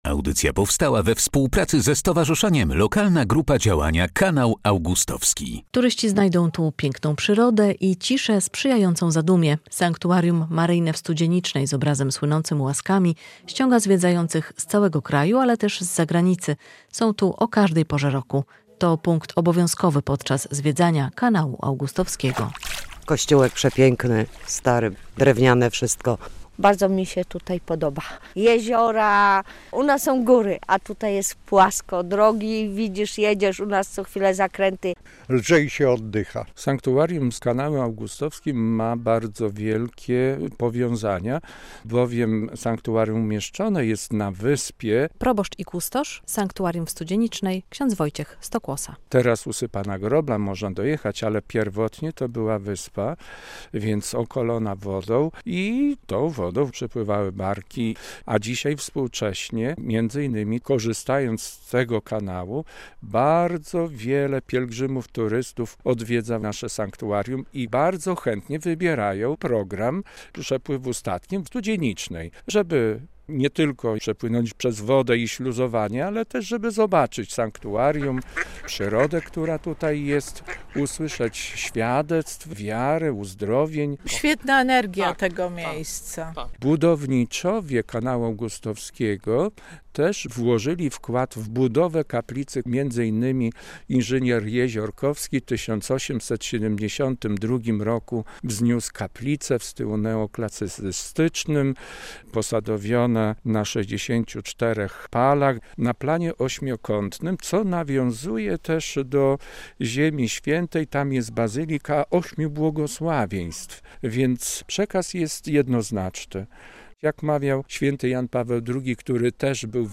Sanktuarium Maryjne w Studzienicznej - relacja